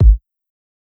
KICK_GRITS.wav